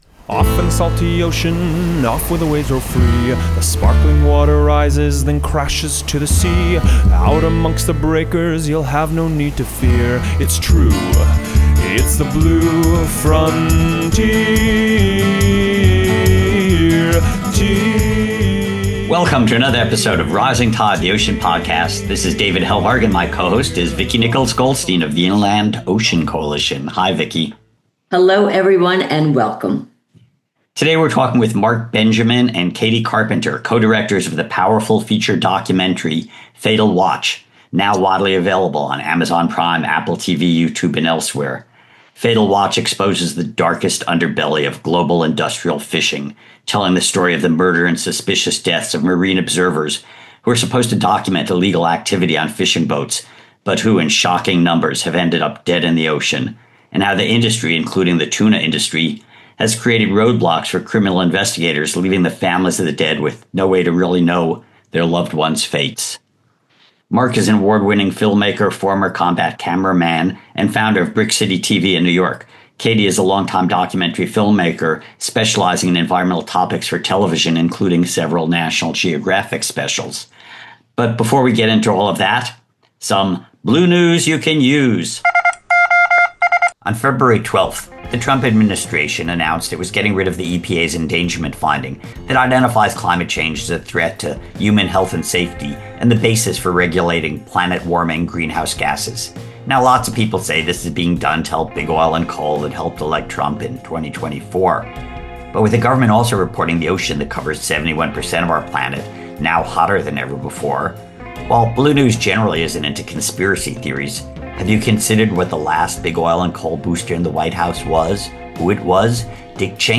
After listening to this wide-ranging and revealing conversation, audiences will undoubtedly want to experience the film for themselves.